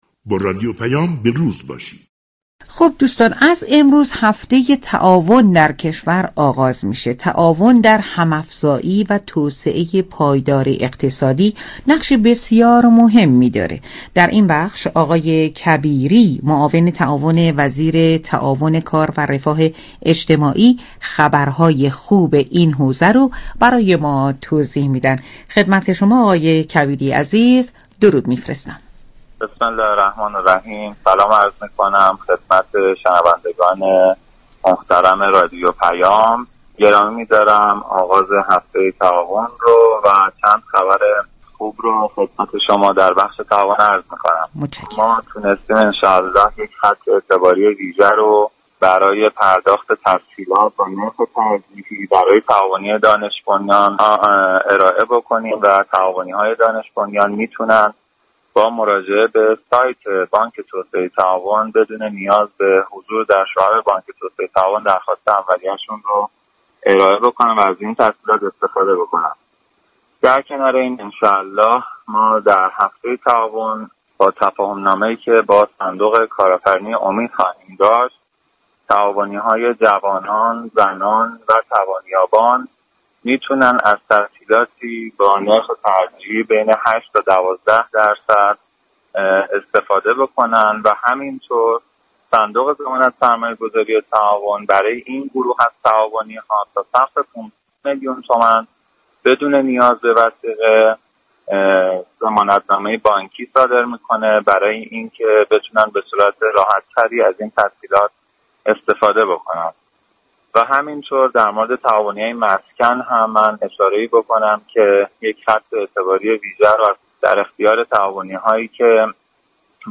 كبیری معاون امور تعاون وزارت تعاون، كار و رفاه اجتماعی در گفتگو با رادیو پیام ، از اعطای تسهیلات با نرخ ترجیحی ،به تعاونی های دانش بنیان خبر داد .